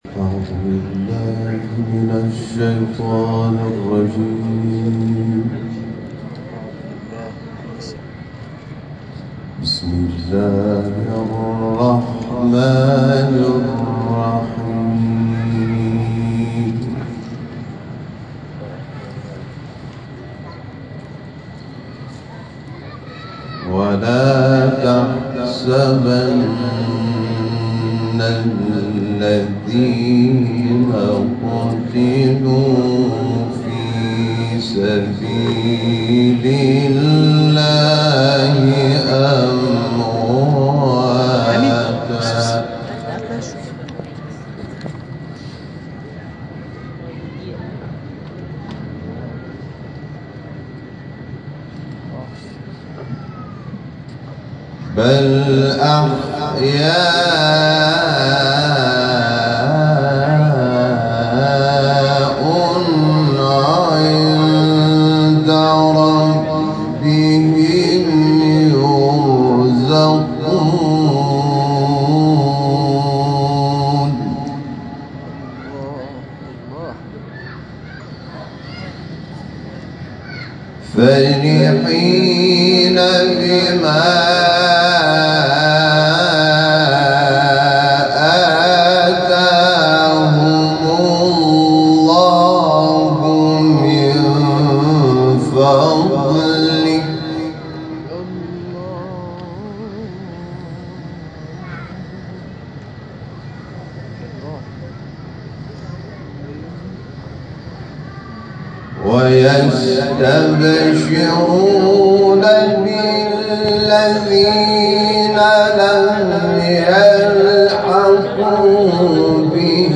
در این مراسم، حامد شاکرنژاد، قاری ممتاز کشورمان به تلاوت آیاتی از کلام الله مجید پرداخت.